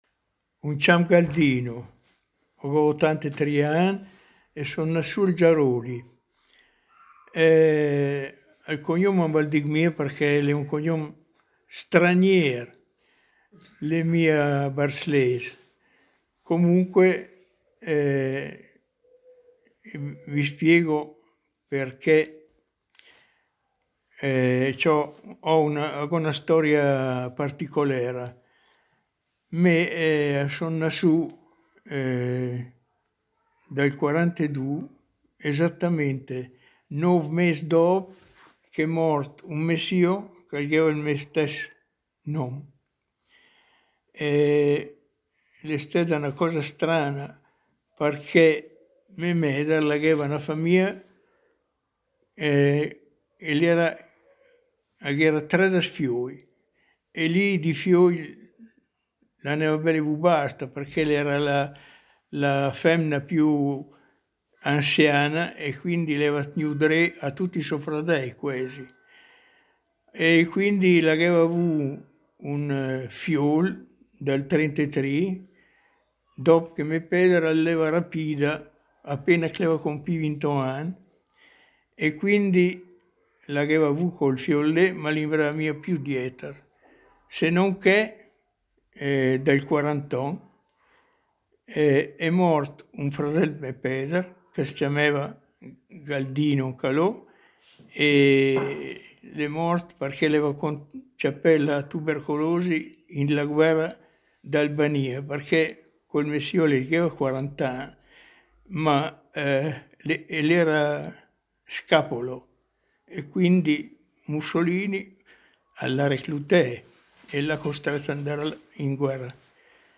Tuttavia, in seconda istanza, abbiamo anche sfruttato le molte conoscenze dirette che abbiamo, come gruppo di Léngua Mêdra, con persone parlanti dialetti ben caratterizzati.